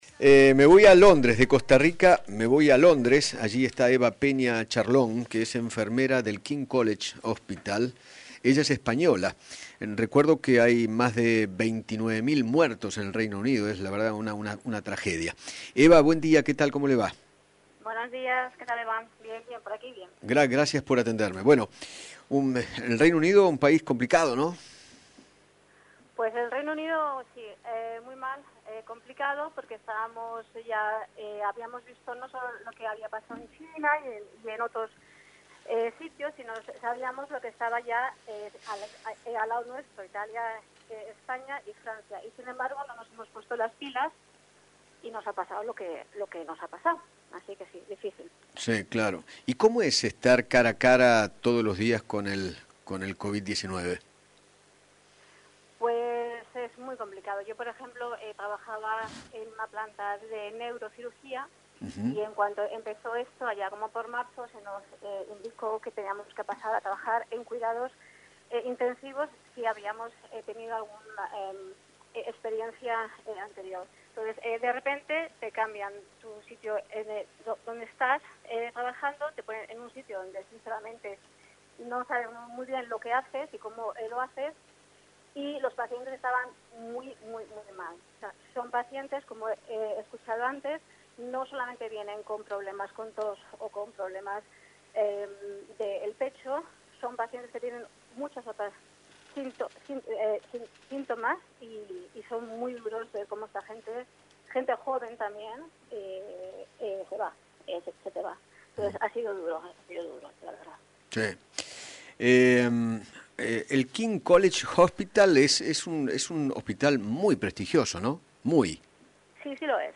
dialogó con Eduardo Feinmann sobre la continua evolución del Coronavirus en Inglaterra y manifestó que si la gente sigue andando en la calle “es muy complicado frenarla”.